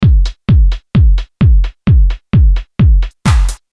130-bpm-techno-drum-loop.mp3